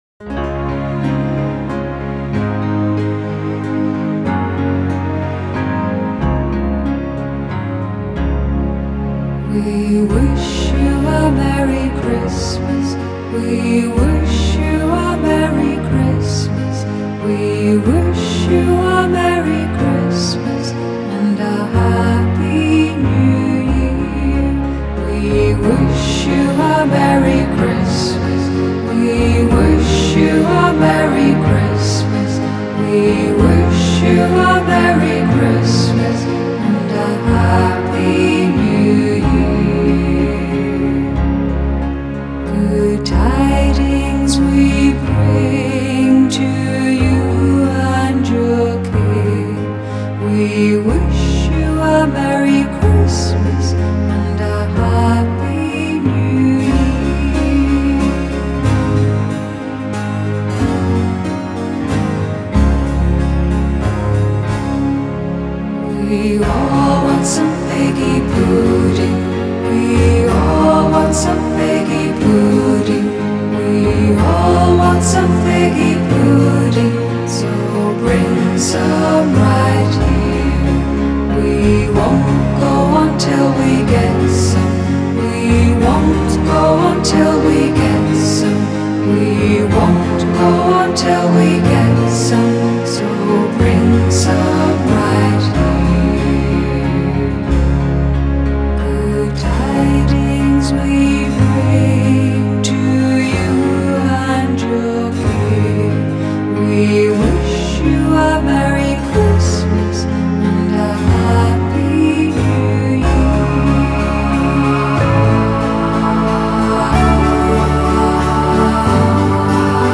ирландской певицы
Рождественский хорал